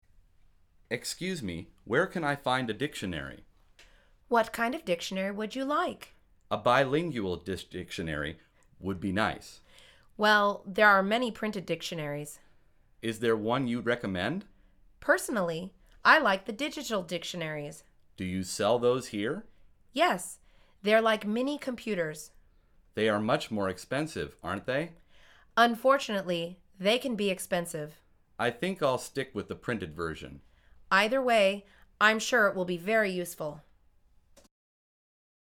کاردو‌آنلاین |مجموعه مکالمات ساده و آسان انگلیسی: فرهنگ لغت انگلیسی